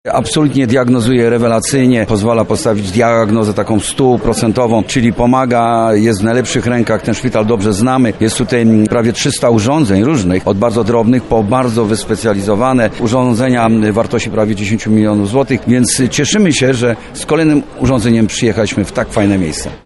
– Urządzenie było tutaj potrzebne i na pewno będzie służyło wiele lat – mówi Jerzy Owsiak, prezes zarządu fundacji.